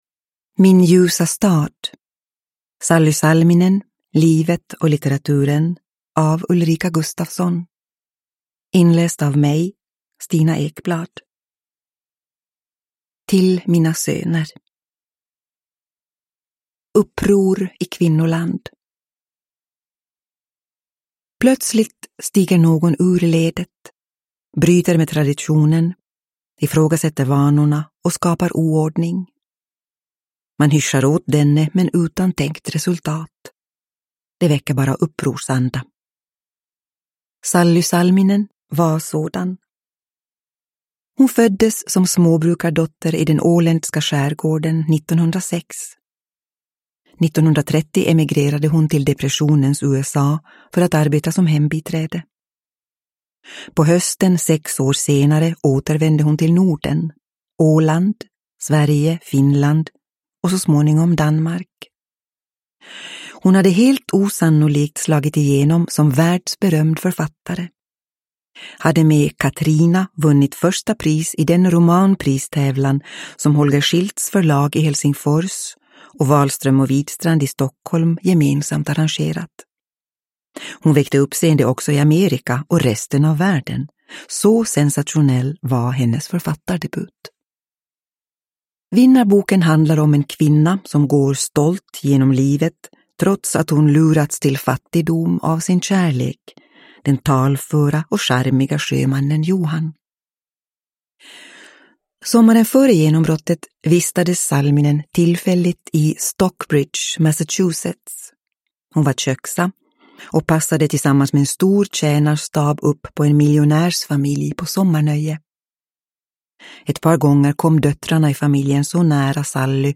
Min ljusa stad – Ljudbok – Laddas ner
Uppläsare: Stina Ekblad